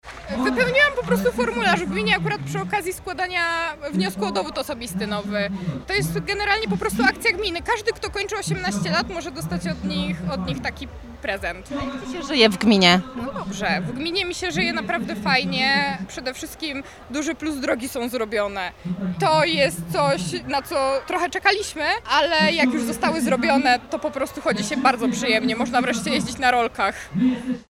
W Parku w Szczodrem odbył się piknik, dopisały frekwencja oraz pogoda.